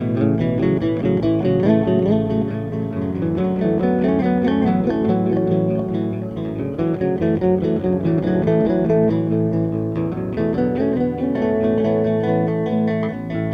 The crickets are fantastic in Georgia this time of year.
rural_twilight_loop.mp3